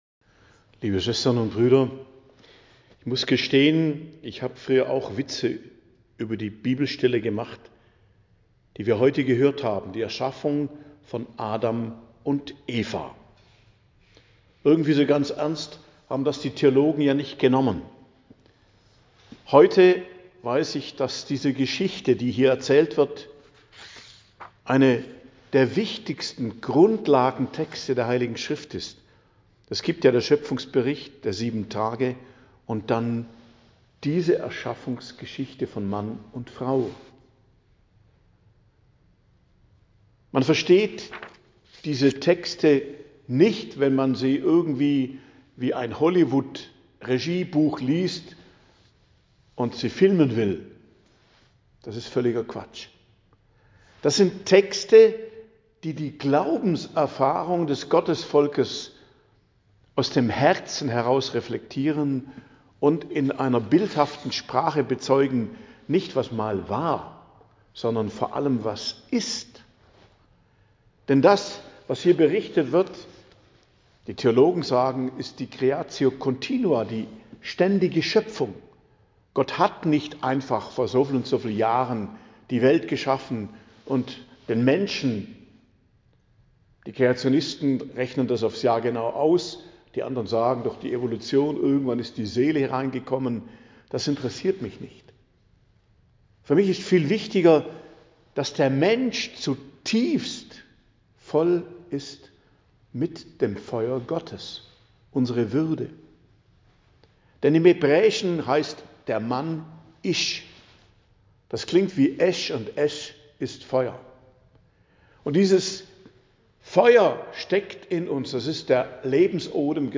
Predigt am Donnerstag der 5. Woche i.J. 13.02.2025